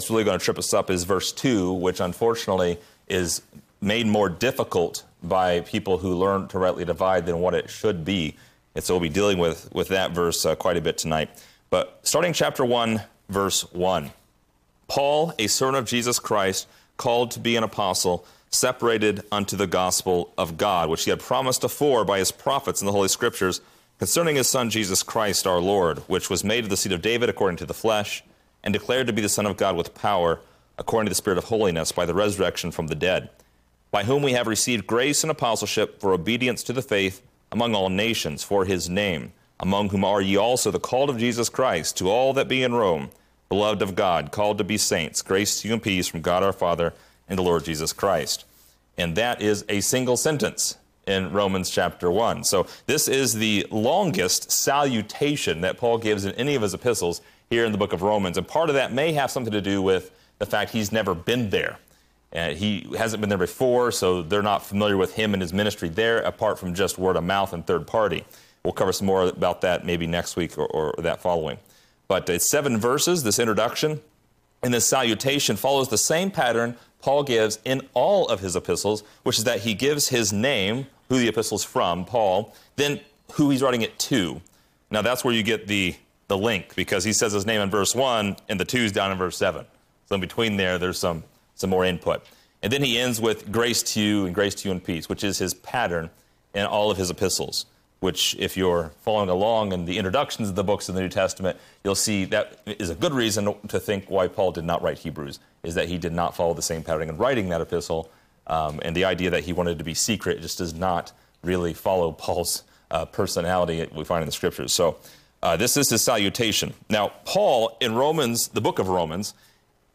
Description: This lesson is part 2 in a verse by verse study through Romans titled: Separated Unto the Gospel.